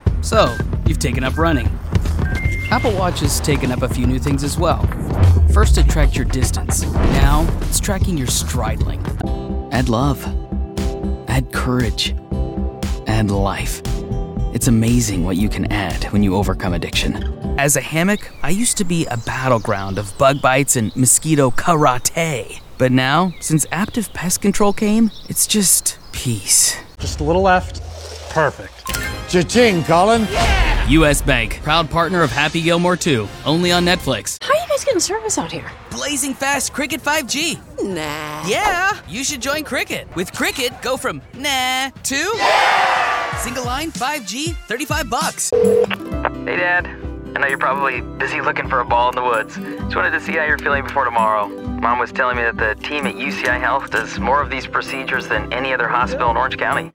Sa voix naturelle est claire, nette et jeune. Son débit est conversationnel et optimiste avec un petit côté humoristique.
Énergique
Sérieuse
Réfléchi